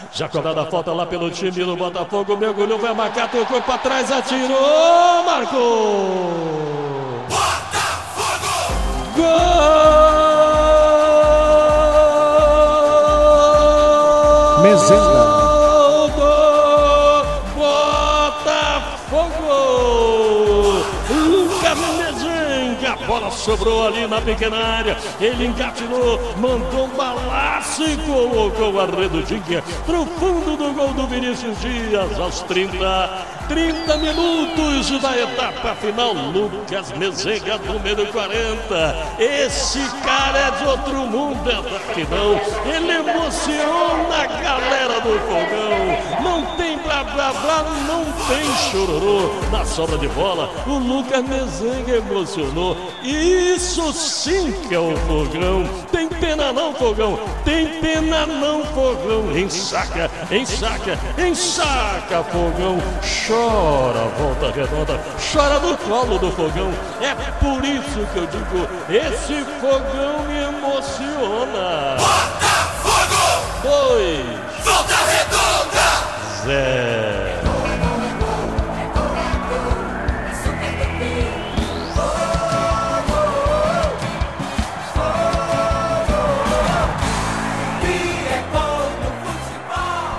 narração